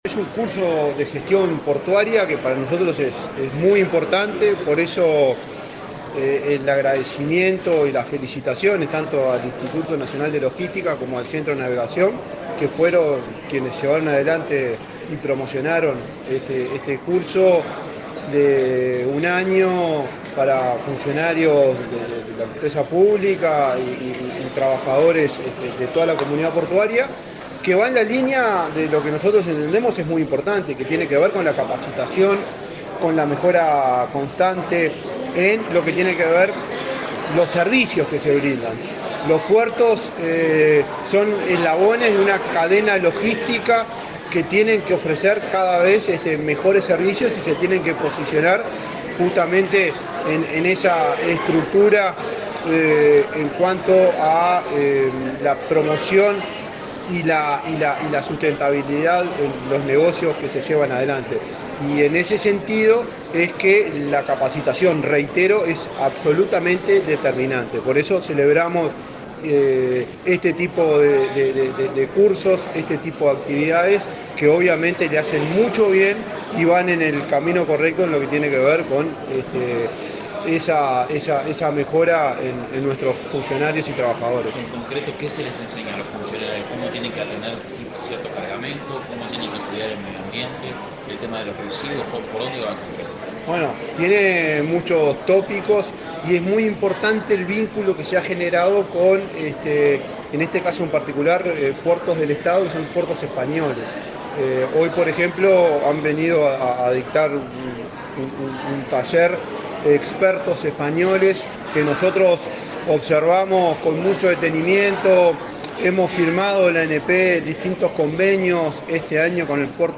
Declaraciones del presidente de la ANP, Juan Curbelo
El presidente de la Administración Nacional de Puertos (ANP), Juan Curbelo, dialogó con la prensa, este miércoles 29 en Montevideo, luego de disertar